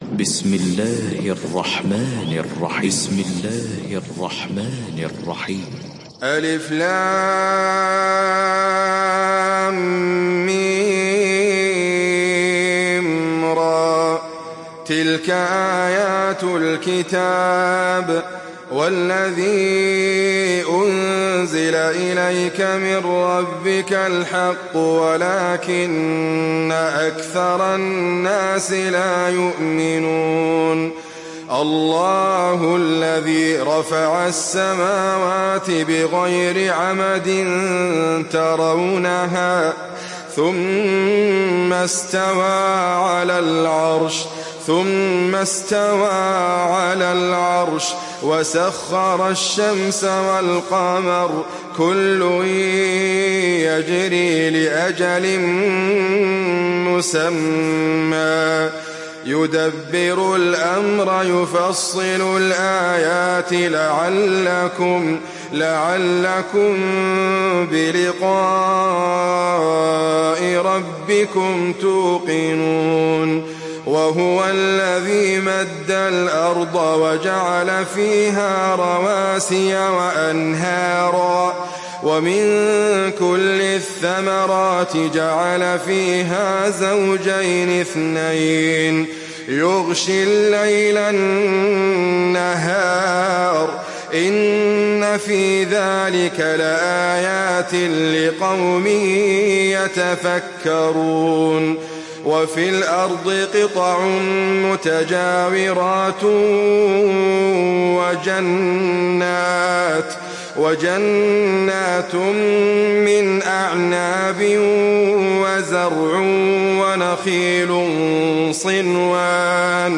সূরা আর-রা‘দ ডাউনলোড mp3 Idriss Abkar উপন্যাস Hafs থেকে Asim, ডাউনলোড করুন এবং কুরআন শুনুন mp3 সম্পূর্ণ সরাসরি লিঙ্ক